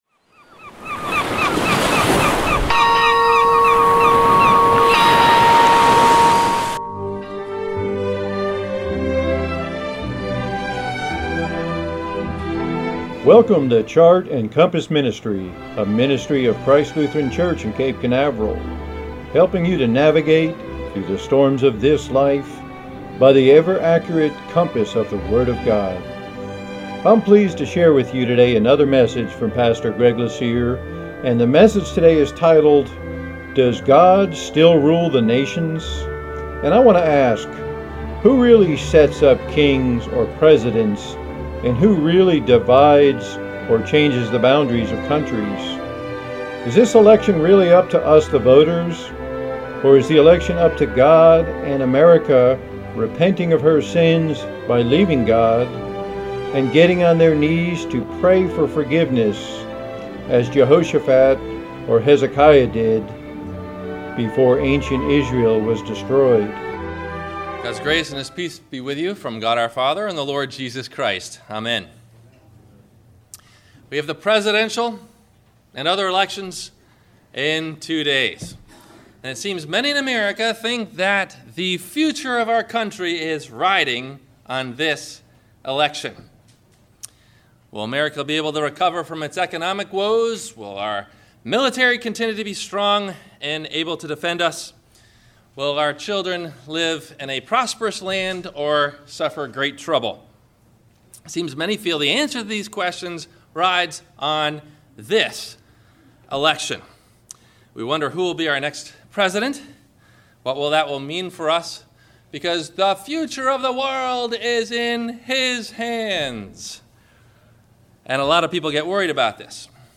Does God Still Rule the Nations? – WMIE Radio Sermon – November 07 2016 - Christ Lutheran Cape Canaveral